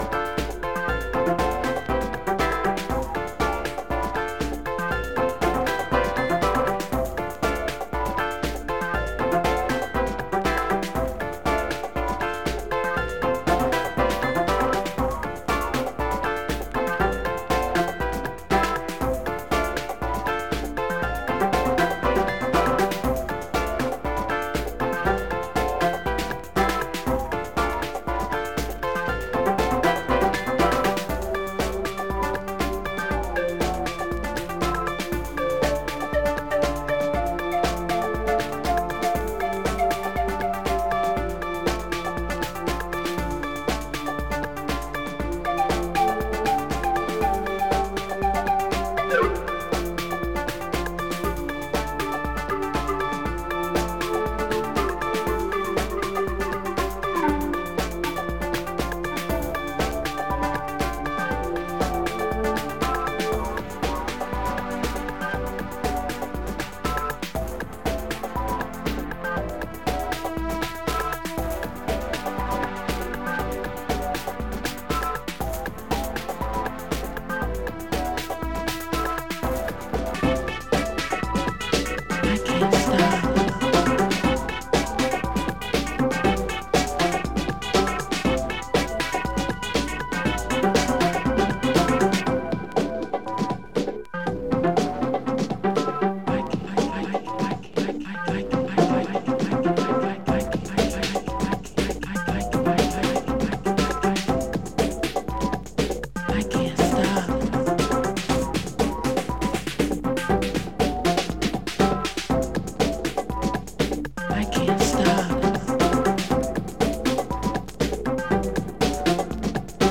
Surface marks and scratches cause some noise on playback.